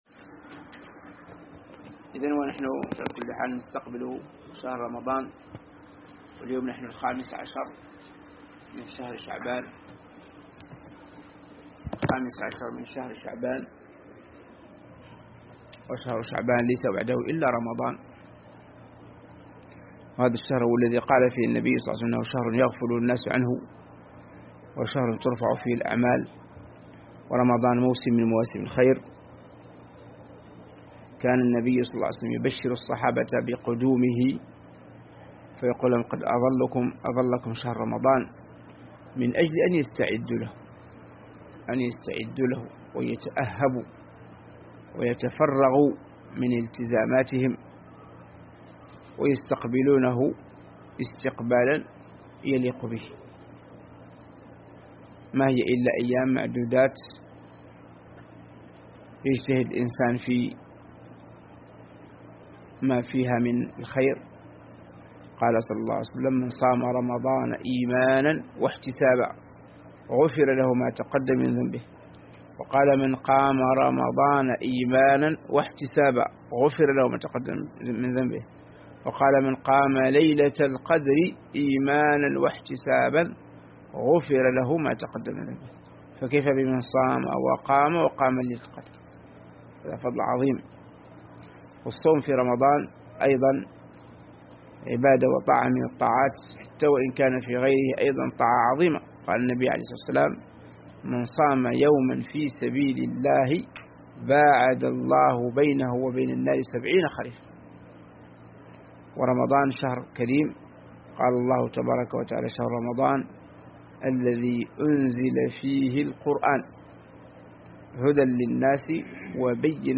محاضرة
مكة المكرمة عقب التعليق على كتاب الوابل الصيب للإمام ابن القيم رحمه الله